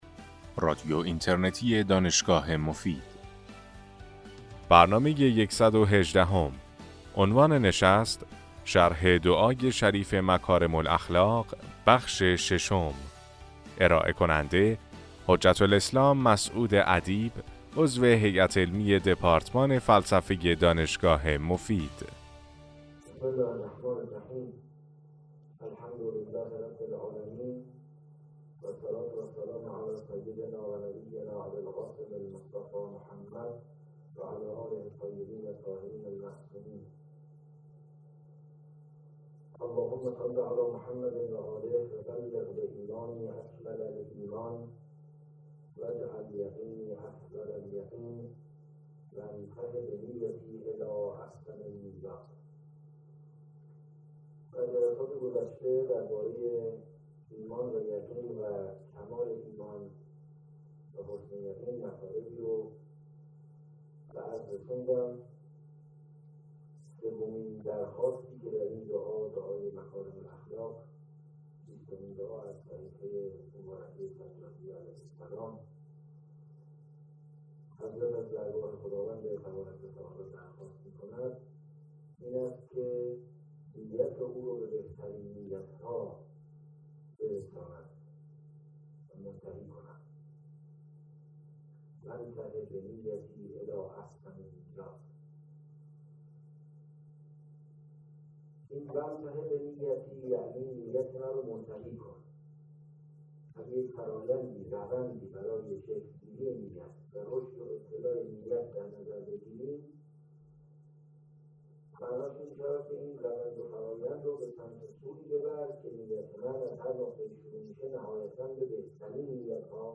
سلسله سخنرانی
در ماه رمضان سال 1395 ایراد شده است